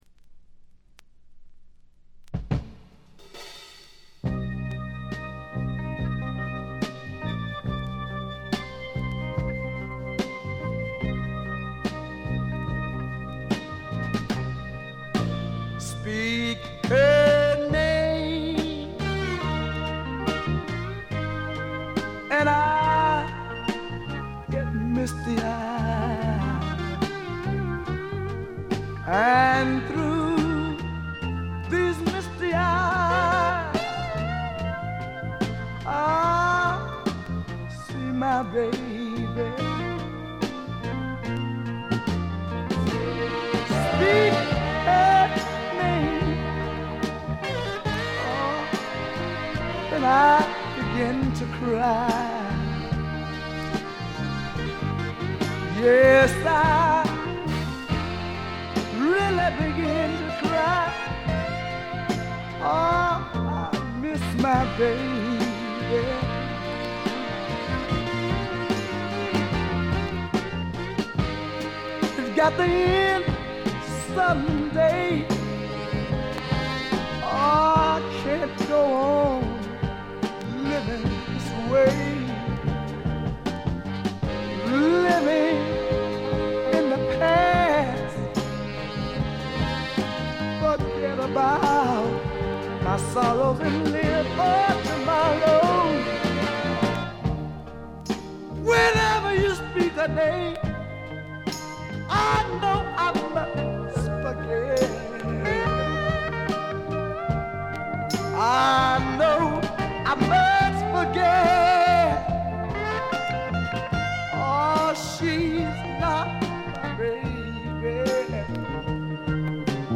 ところどころでチリプチ。
いうまでもなく栄光のサザンソウル伝説の一枚です。
試聴曲は現品からの取り込み音源です。